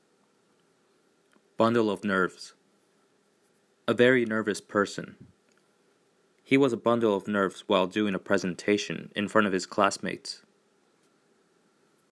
、 英語ネイティブによる発音は下記のリンクをクリックしてください。